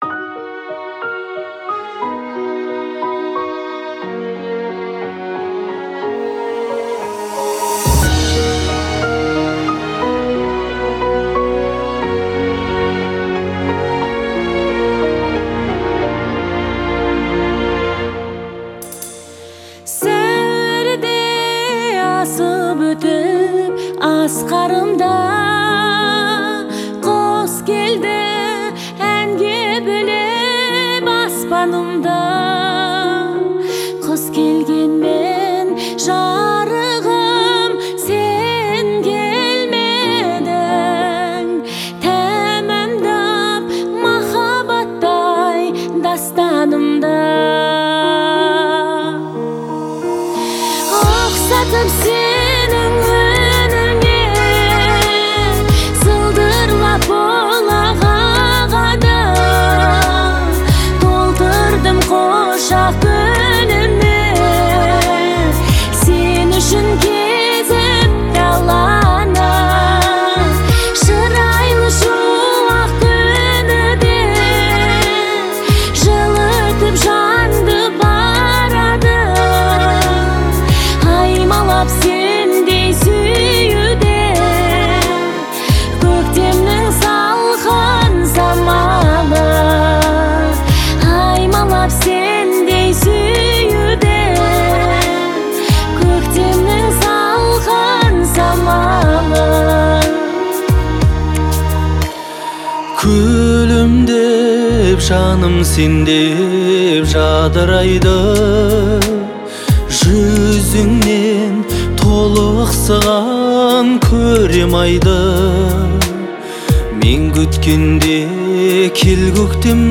это трогательная казахская песня в жанре народной музыки